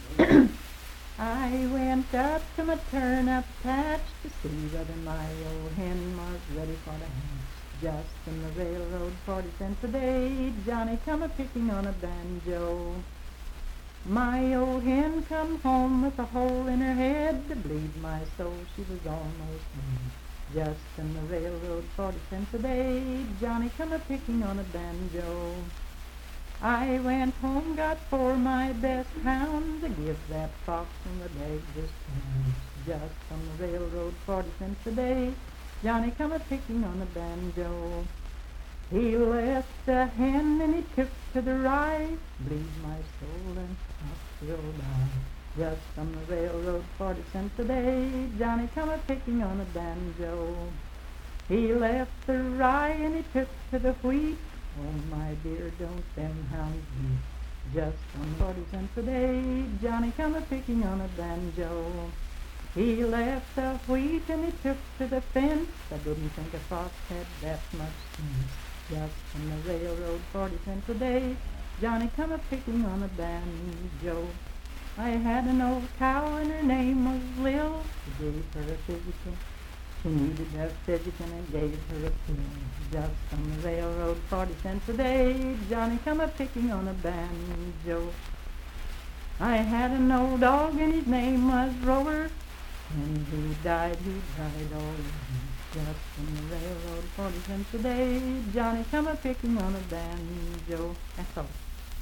Unaccompanied vocal music
Verse-refrain 8(4w/R).
Voice (sung)